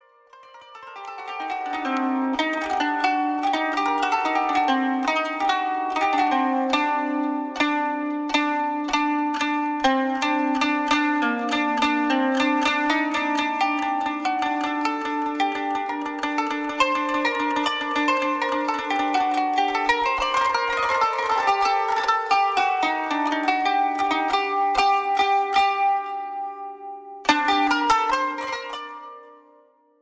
Qanun Taqsim